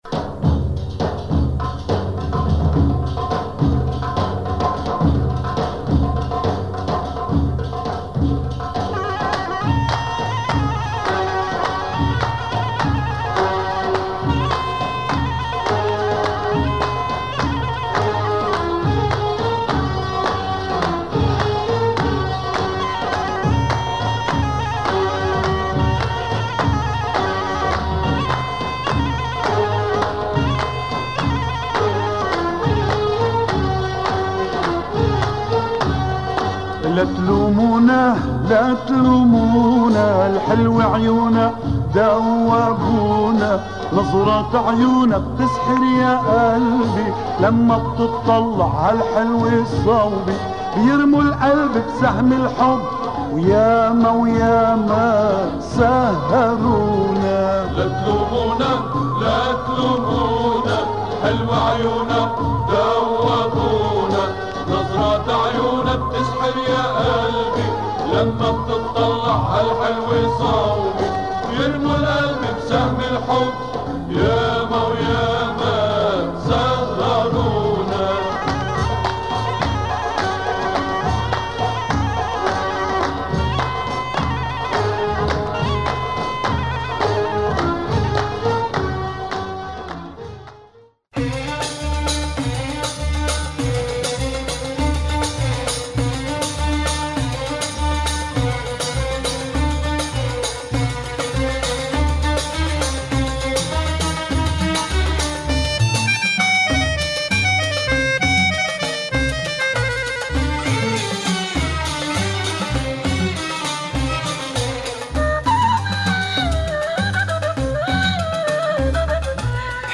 Some more Lebanese beats